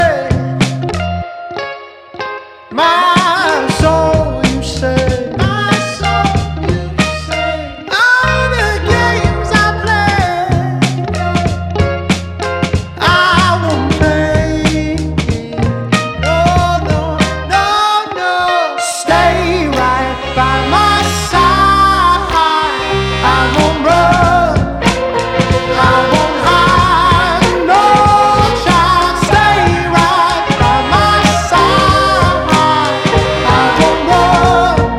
Жанр: Рок
# Rock